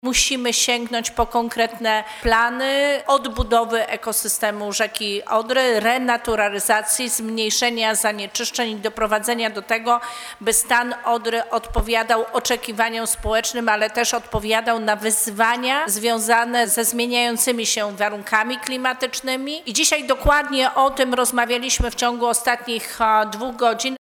W Dolnośląskim Urzędzie Wojewódzkim odbyła się we wtorek debata Ministerstwa Klimatu i Środowiska z przedstawicielami samorządów oraz podmiotami publicznymi nt. współpracy w zakresie zapobiegania zanieczyszczeniom w rzece Odrze.